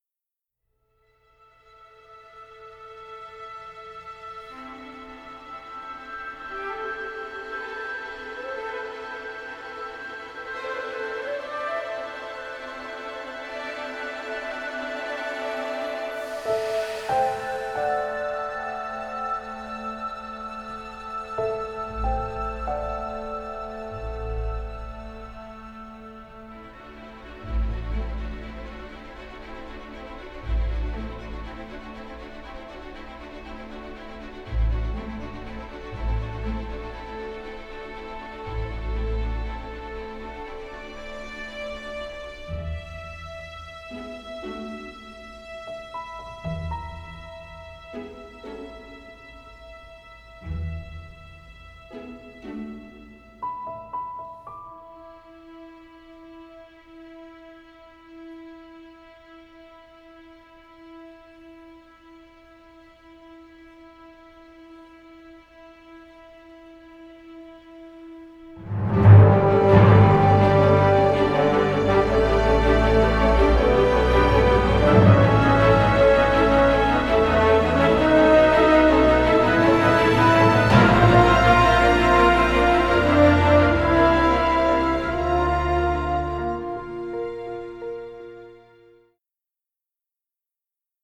orchestral score which offers extremely wide range of colors